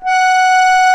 F#5 ACCORD-L.wav